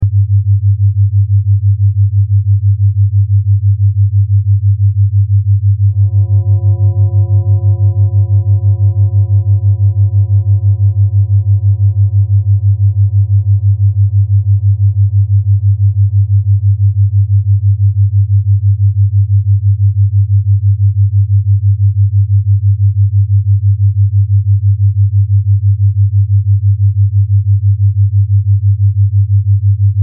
This Mp3 Sound Effect Struggling to think straight? This brown noise helped me shut out the fog.